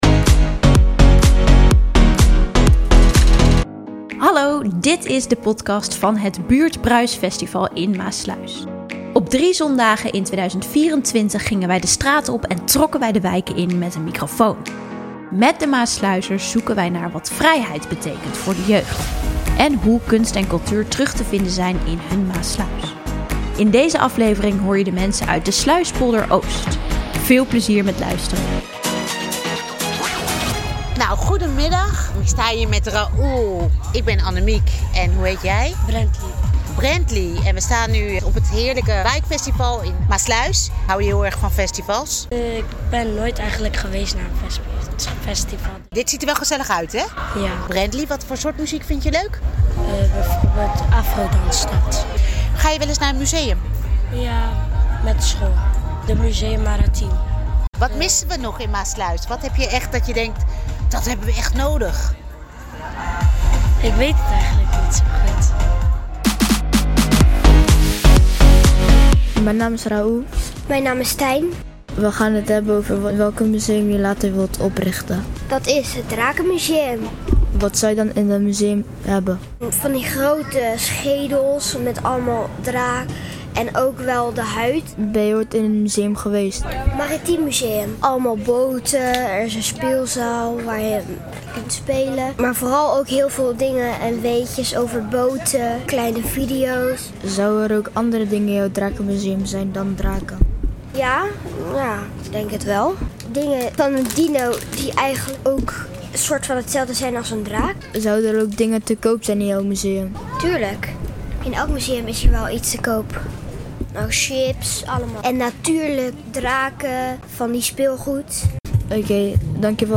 Op 3 zondagen in 2024 werd het culturele festival Buurbruis georganiseerd in verschillende wijken van Maassluis. Wij gingen de straat op en de wijken in met een microfoon, en vroegen naar wat 'Vrijheid' voor de Maassluizers betekent.